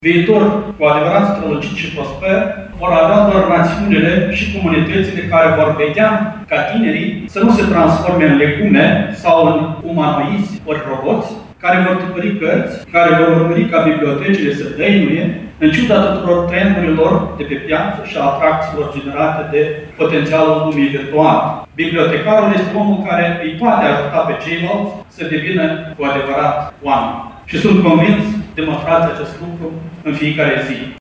în dechiderea conferinței